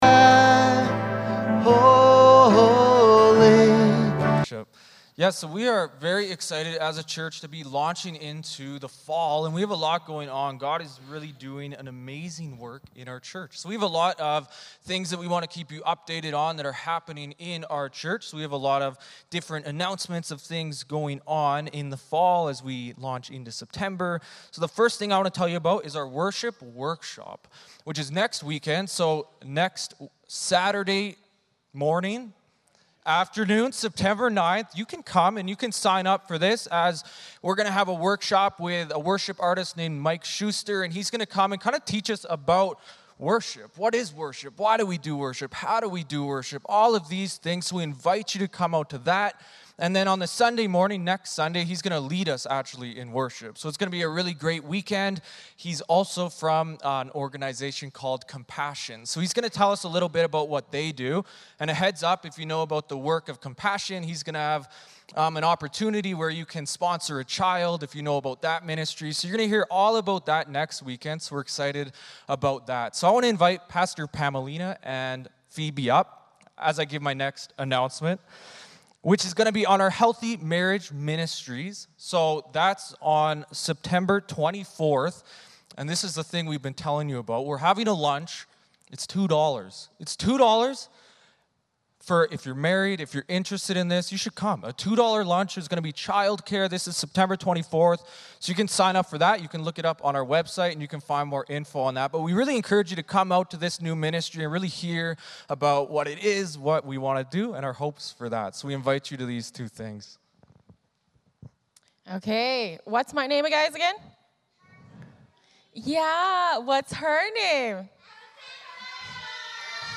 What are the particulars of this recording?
1 Thessalonians 5:12-28 Service Type: Sunday Morning Service Passage